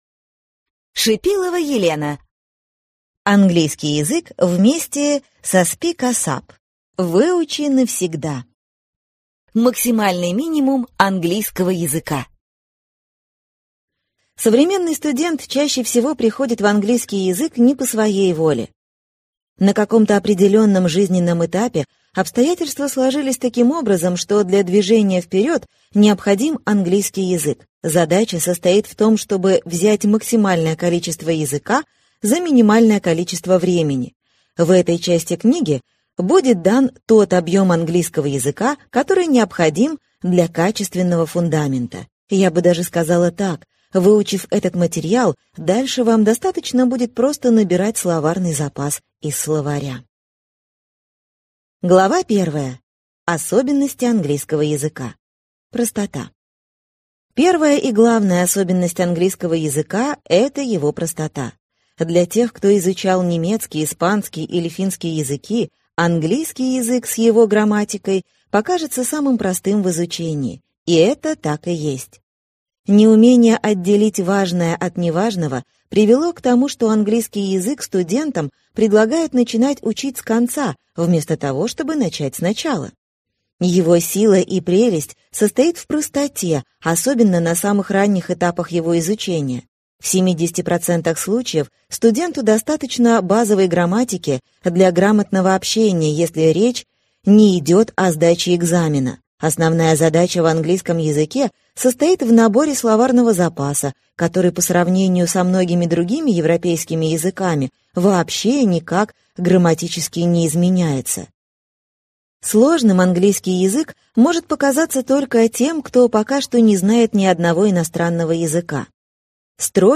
Аудиокнига Английский язык вместе с SpeakASAP. Выучи навсегда. Максимальный минимум английского языка | Библиотека аудиокниг